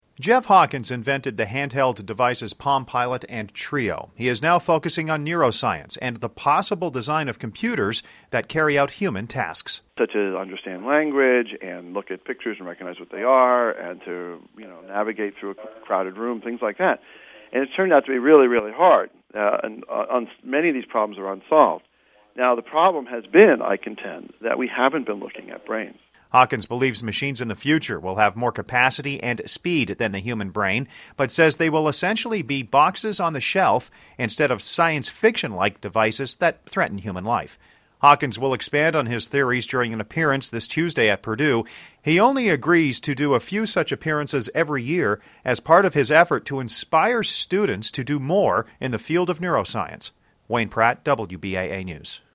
WEST LAFAYETTE, Ind. - Jeff Hawkins, the architect of the PalmPilot and founder of three technology companies, spoke at a teleconference for local media on Thursday (Nov. 9).